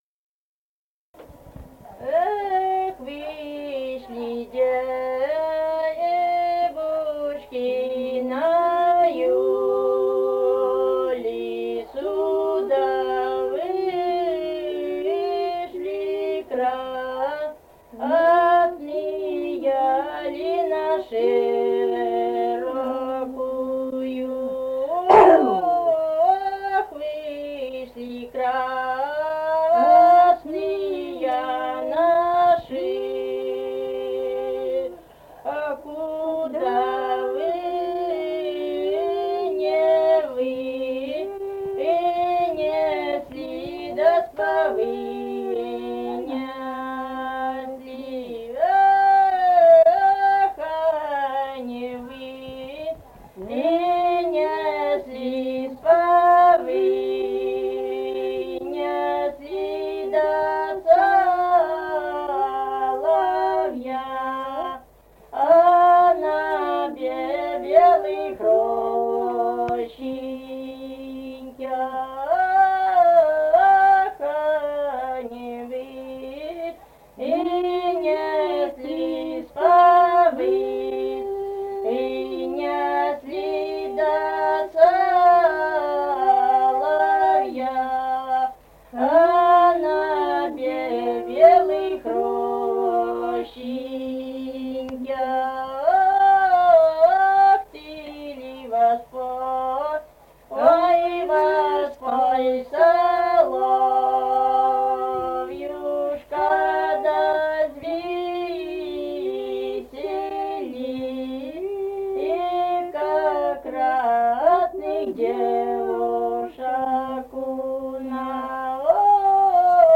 Республика Казахстан, Восточно-Казахстанская обл., Катон-Карагайский р-н, с. Белое, июль 1978.